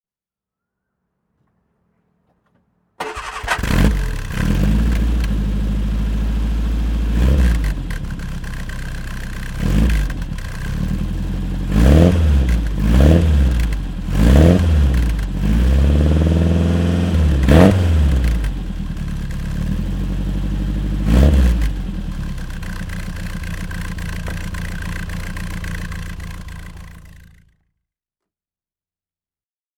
Turner Mark III (1964) - Starten und Leerlauf